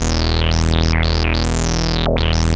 Index of /90_sSampleCDs/Hollywood Edge - Giorgio Moroder Rare Synthesizer Collection/Partition A/ARP 2600 7